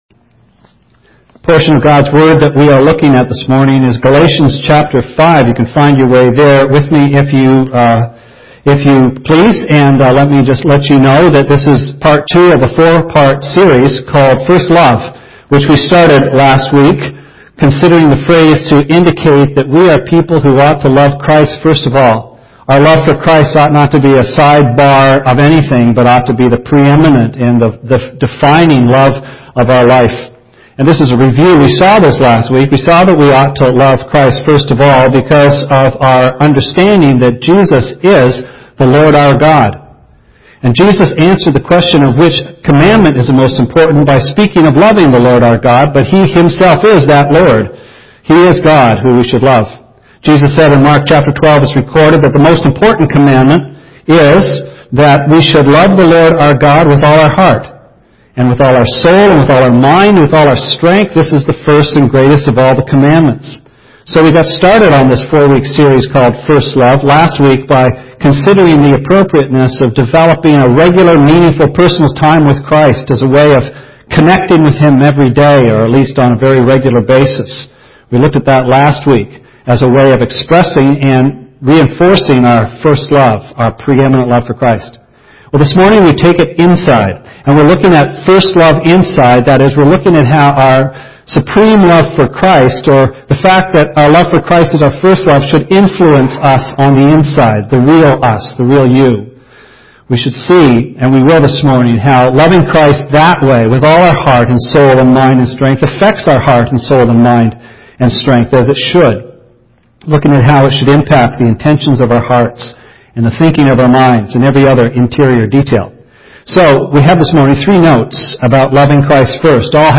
Sermon Archives - West London Alliance Church